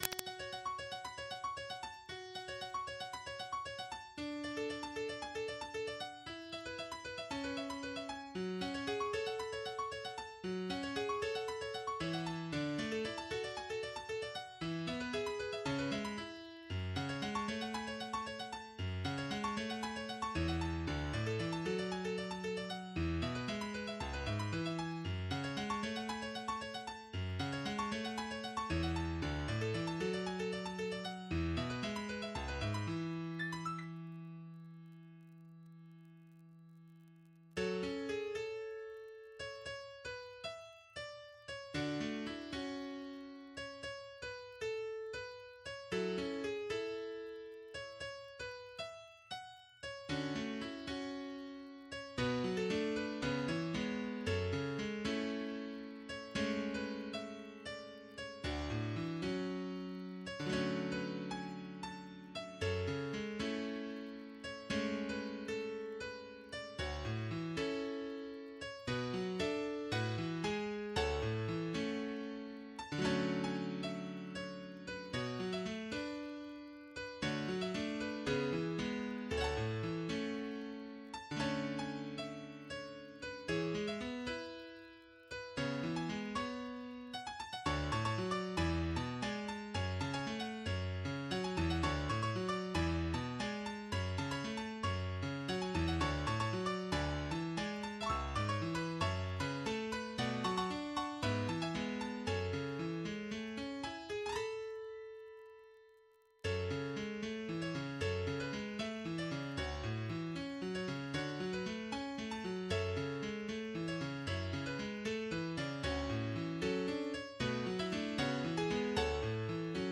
MIDI 13.94 KB MP3
EDM song